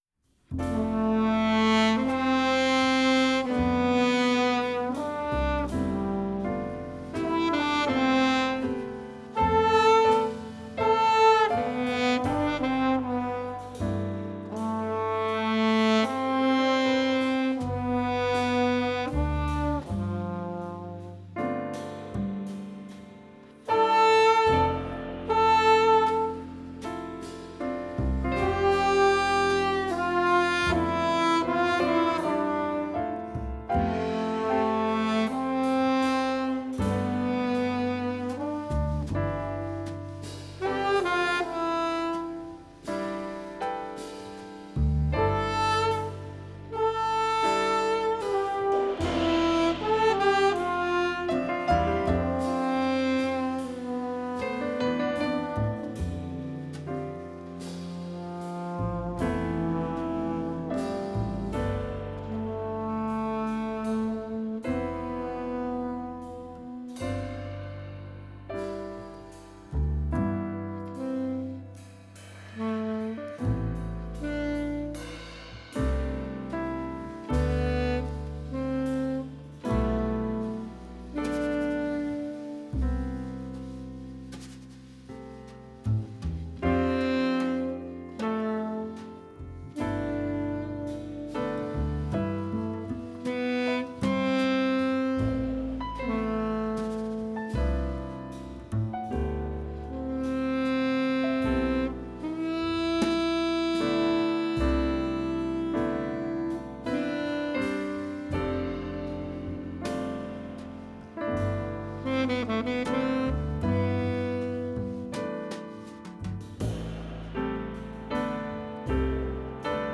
Reeds
Trombone
Piano
Bass
Drums